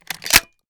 Decay/sound/weapons/arccw_ud/m1014/shell-insert-02.ogg at main
shell-insert-02.ogg